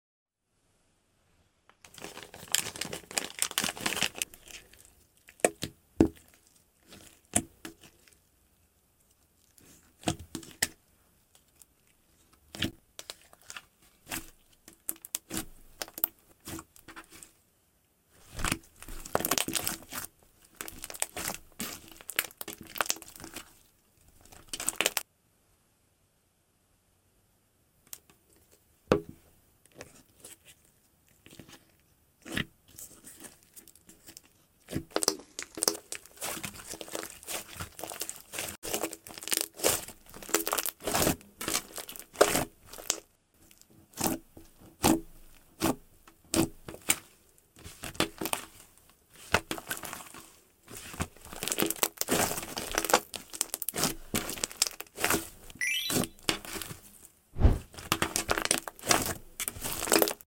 Soft Jelly Mochi~ Slime ASMR sound effects free download